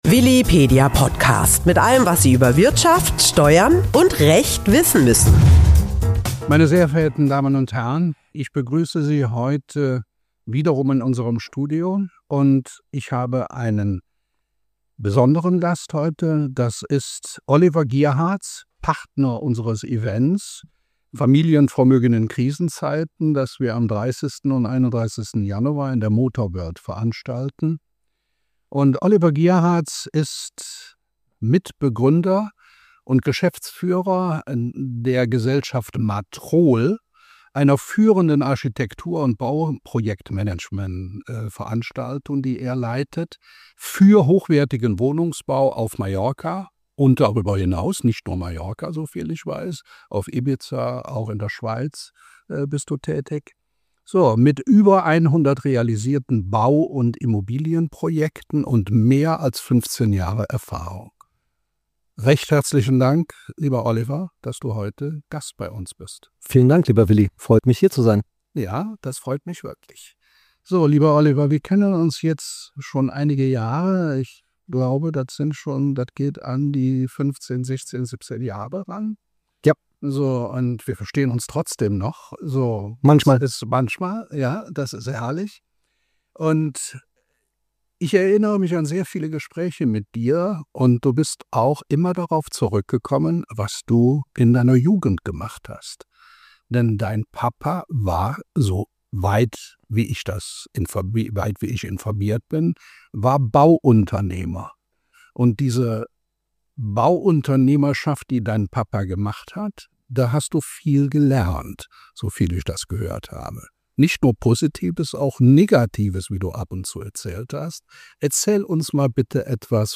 Im Podcast sprechen die beiden offen über typische Risiken beim Bauen auf Mallorca, über Qualitätsunterschiede am Markt, steigende Baukosten und warum Marktkenntnis entscheidend ist. Auch der Einsatz von Digitalisierung, KI und moderner Technik wie 360-Grad-Dokumentation und VR-Brillen wird thematisiert – nicht als Spielerei, sondern als Werkzeug für Transparenz und Kontrolle.